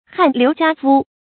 汗流浃肤 hàn liú jiā fū
汗流浃肤发音